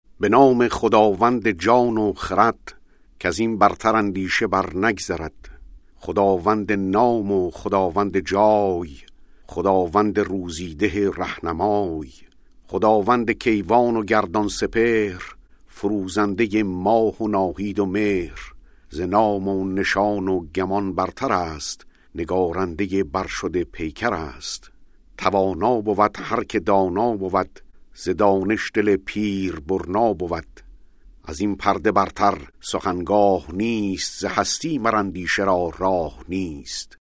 reads the opening lines of the Shahnameh.